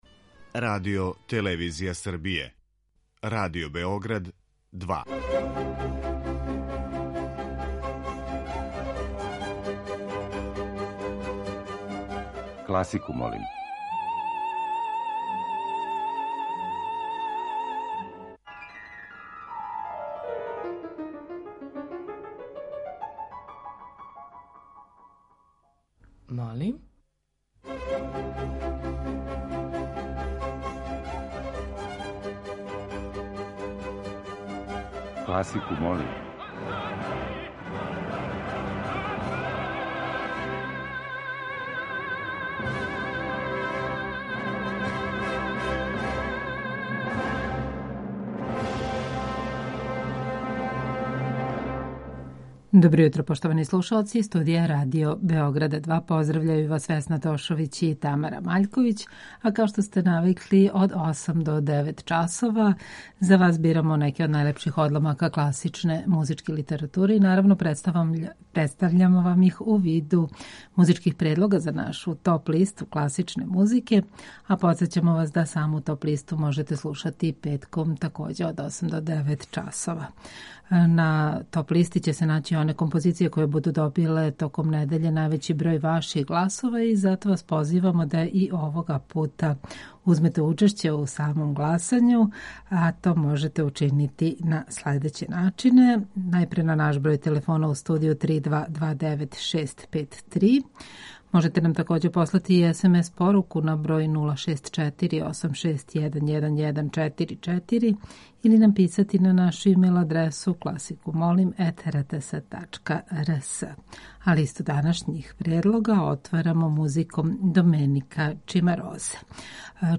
Уживо вођена емисија Класику, молим окренута je широком кругу љубитеља музике и разноврсног је садржаја ‒ подједнако су заступљени сви музички стилови, епохе и жанрови.